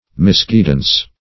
Misguidance \Mis*guid"ance\, n.